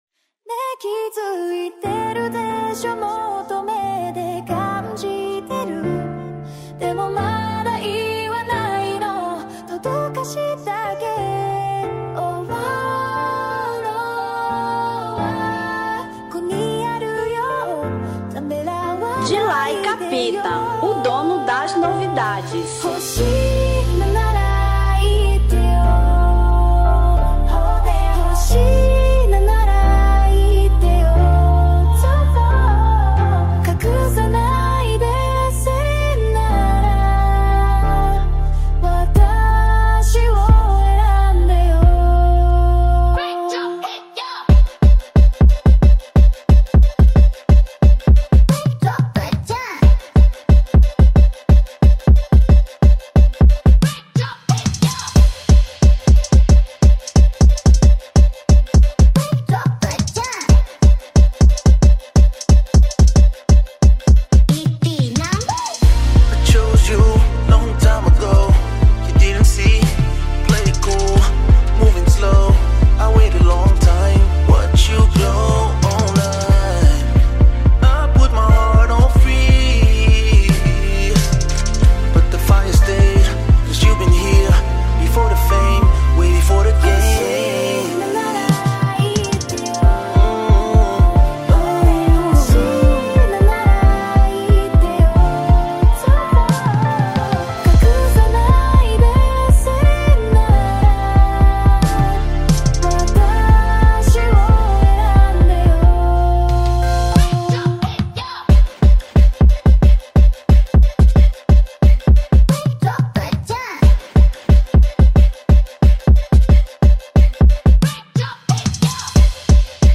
Drill 2025